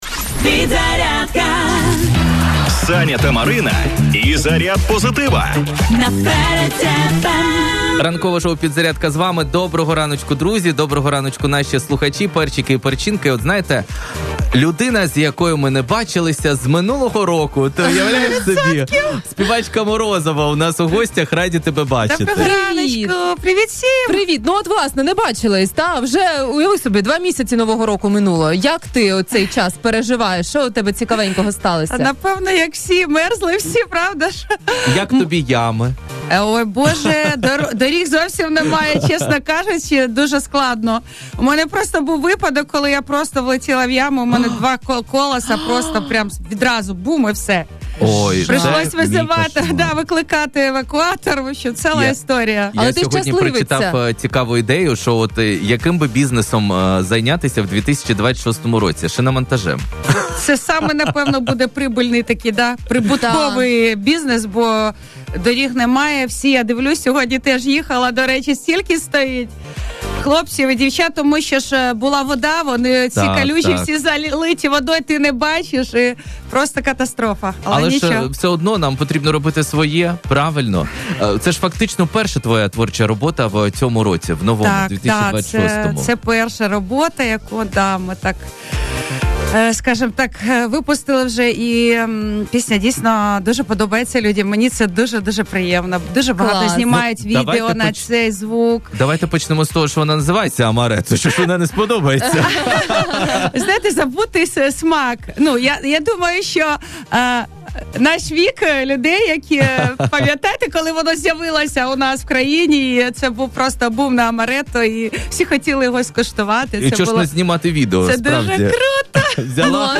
в ефірі ранкового шоу «Підзарядка» на радіо Перець ФМ